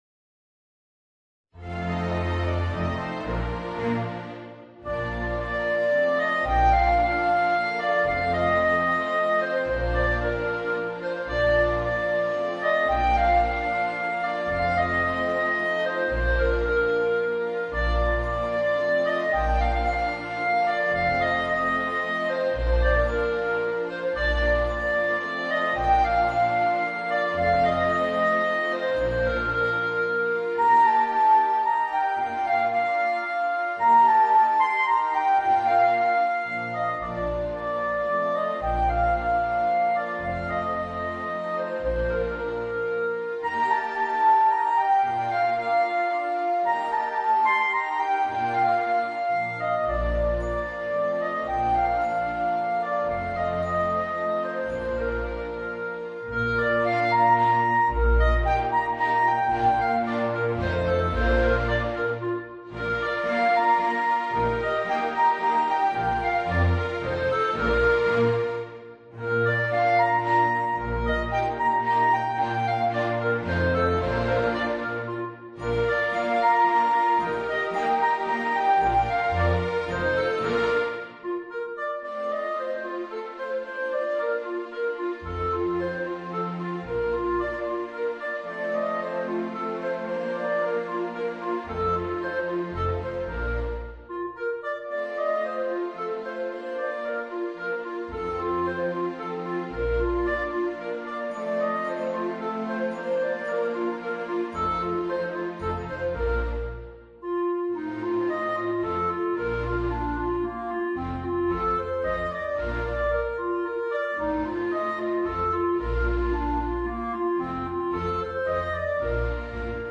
Voicing: Flute and Orchestra